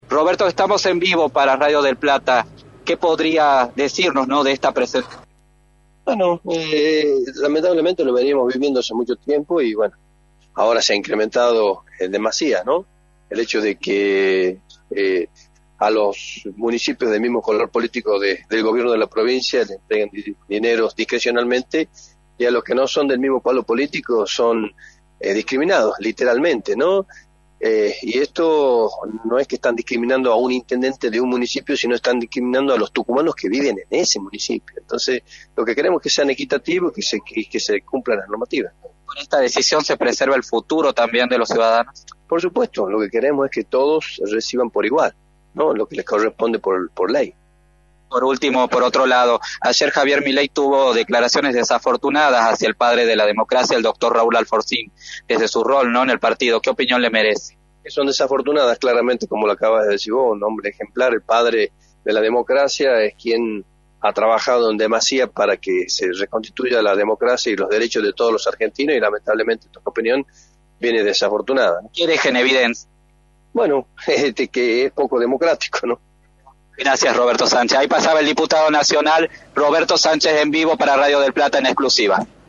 Intendentes y parlamentarios de la oposición presentaron una nota en Casa de Gobierno para exigir explicaciones por la disparidad en la repartición de subsidios para los municipios. Roberto Sánchez, Diputado Nacional, analizó en Radio del Plata Tucumán, por la 93.9, la situación.
«Se ha incrementado demasiado el hecho de que a los municipios del mismo color político del gobierno de la provincia le entreguen dinero manera discrecional y a los que no son del mismo color político son discriminados, y aquí no están discriminando a un Intendente, sino que están discriminando a los tucumanos que viven en ese municipio, entonces lo que queremos es que sean equitativos y que se cumplan las normativas» señaló Roberto Sánchez en entrevista para «La Mañana del Plata» por la 93.9.